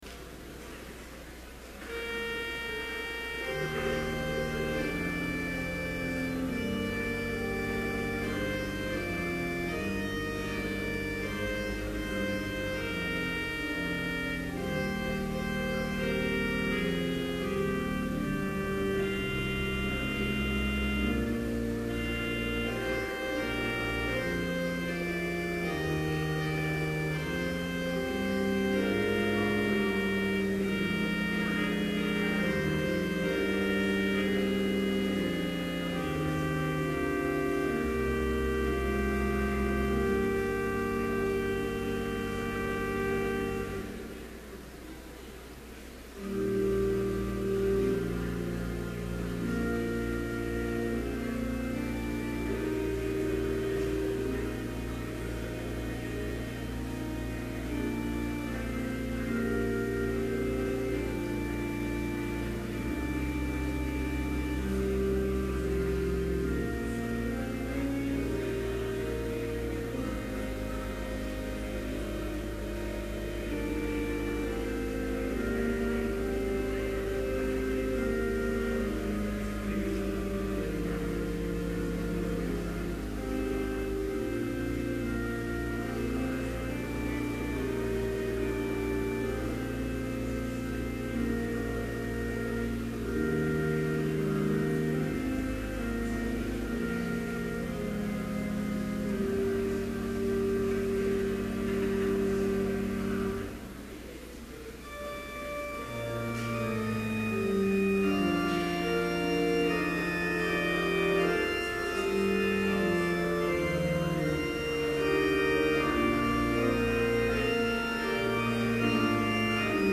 Complete service audio for Chapel - October 18, 2011